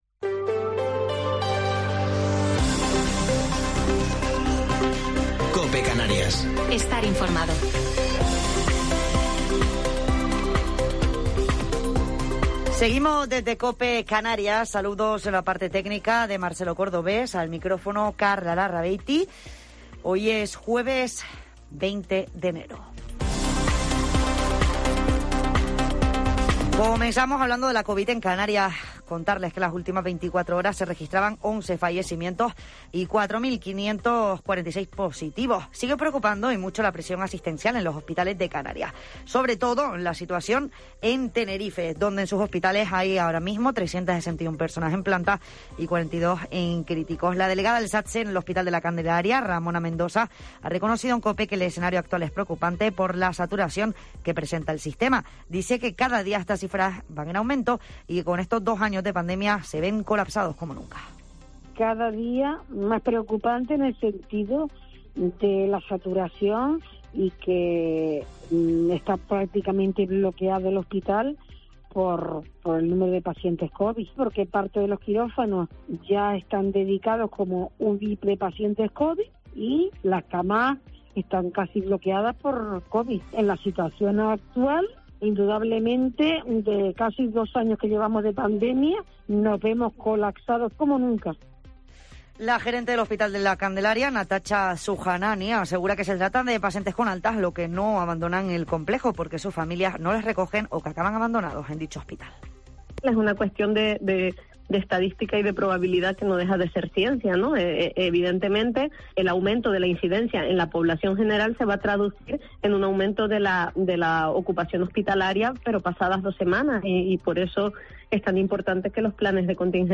Entrevista a Francisco González, alcalde de Icod de los Vinos